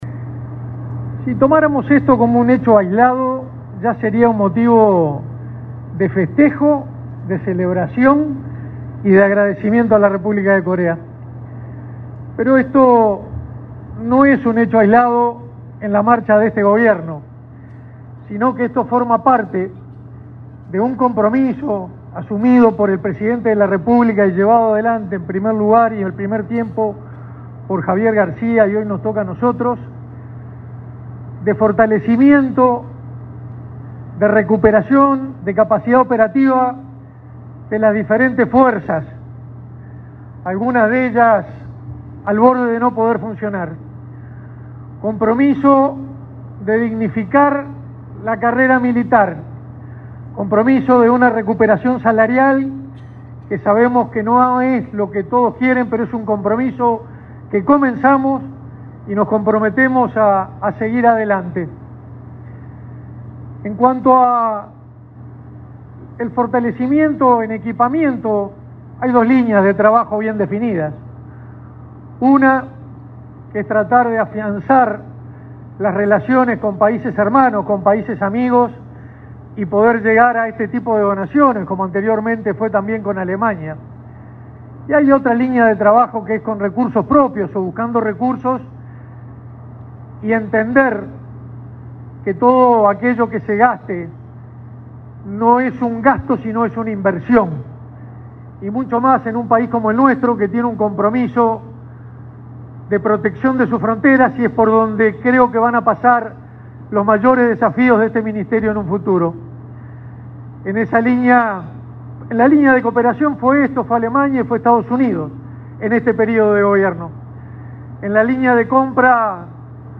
Palabras del ministro de Defensa Nacional, Armando Castaingdebat
Este lunes 21 en el puerto de Montevideo, el ministro de Defensa Nacional, Armando Castaingdebat, se expresó en el acto de incorporación de la lancha